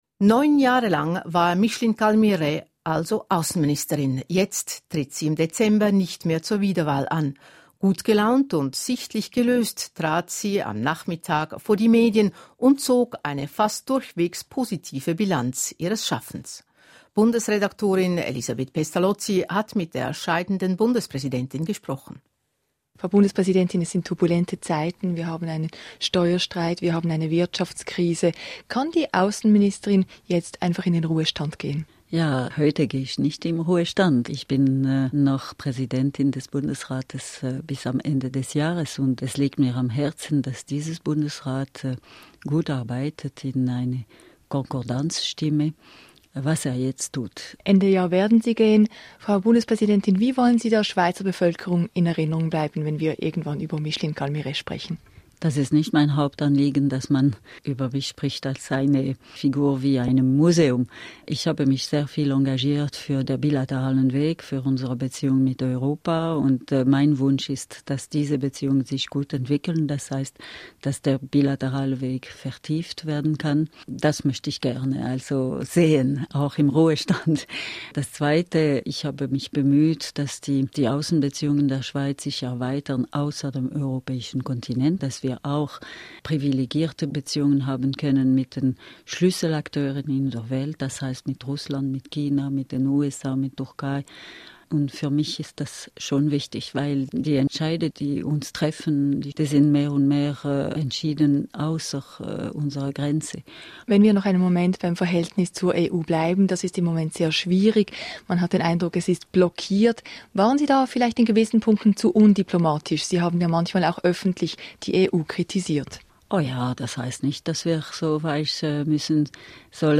Bundespräsidentin Micheline Calmy Rey tritt im Dezember nicht mehr an zur Wiederwahl. Nach neun Jahren im Amt möchte sich die 66jährige mehr Zeit für ihre Familie nehmen. Das Interview.